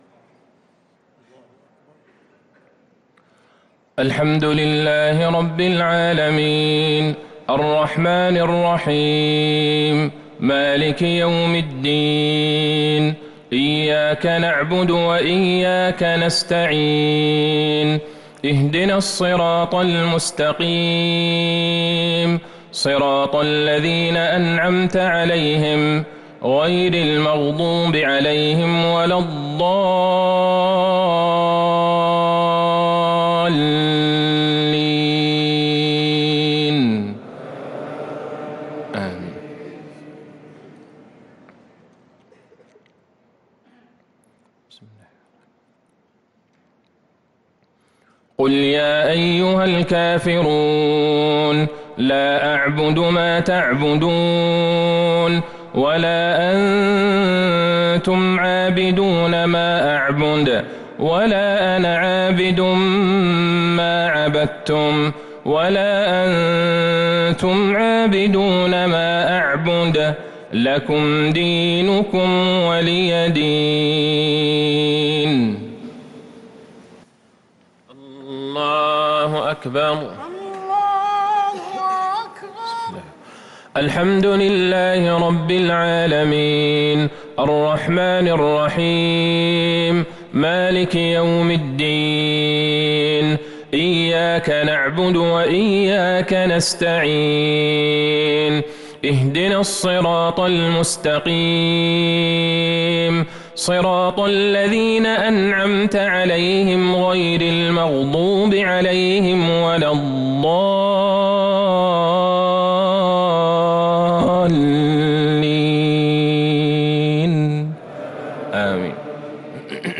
صلاة المغرب للقارئ عبدالله البعيجان 2 رمضان 1443 هـ
تِلَاوَات الْحَرَمَيْن .